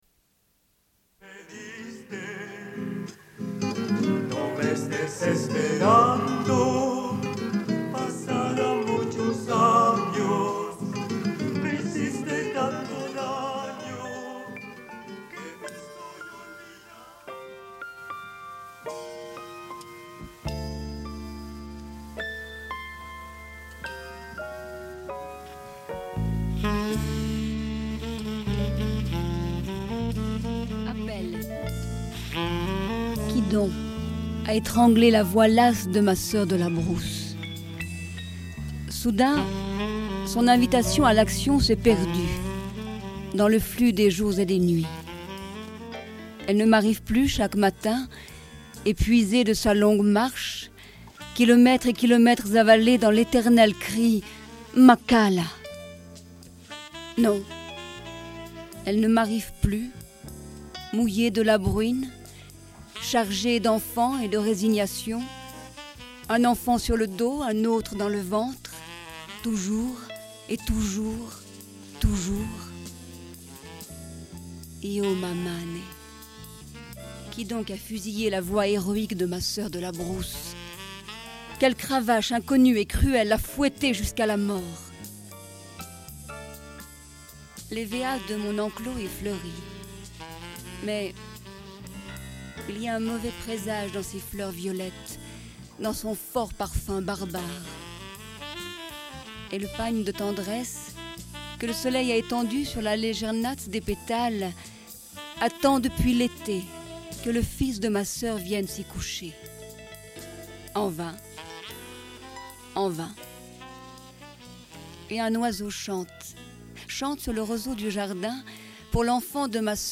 Une cassette audio, face A31:06
Radio Enregistrement sonore